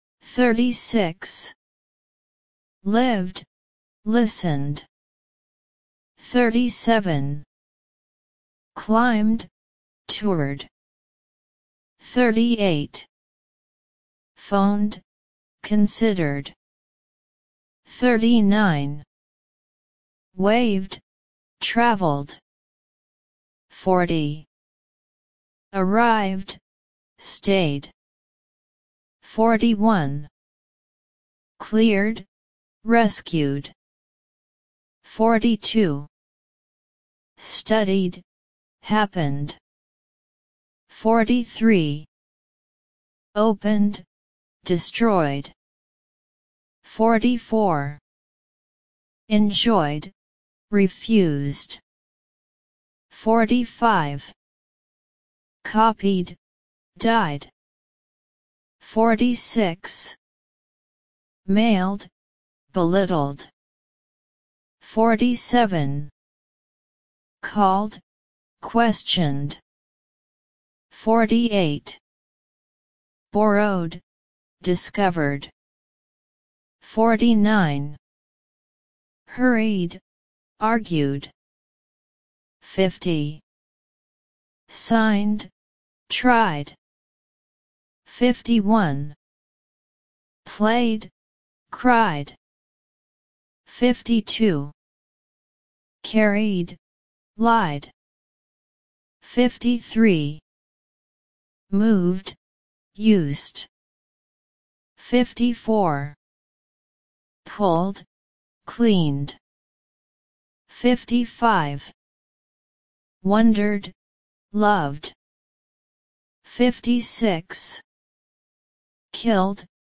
Listen, Read and Repeat: d ending sound
Past-Tense-Regular-Verbs-d-sound-Zira1.mp3